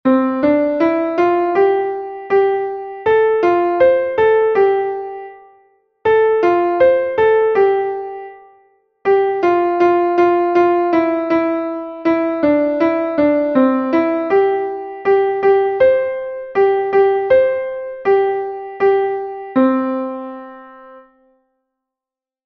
Einstimmige Melodie im Violinschlüssel, C-Dur, 2/4-Takt, mit der 1.
Melodie: Volksweise (nach „Wer die Gans gestohlen hat“)
haeschen-in-der-grube_klavier_melodiemeister.mp3